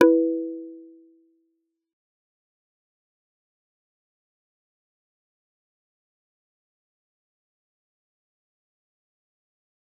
G_Kalimba-E4-f.wav